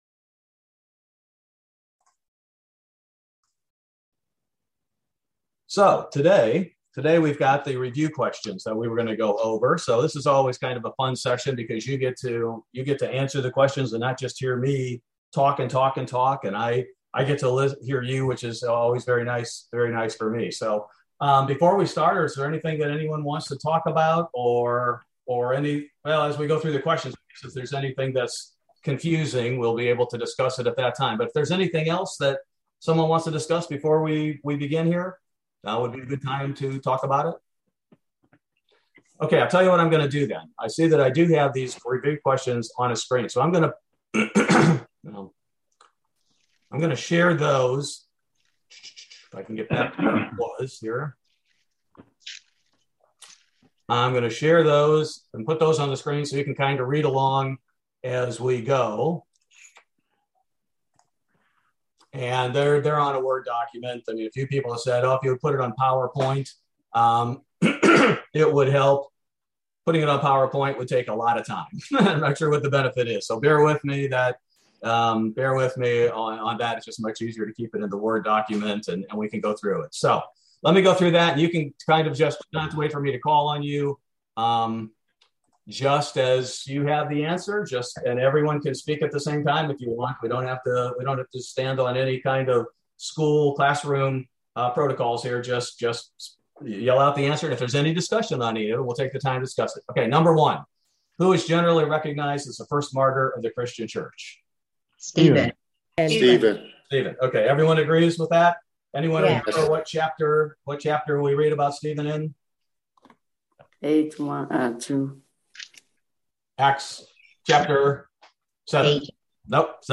Bible Study: October 27, 2021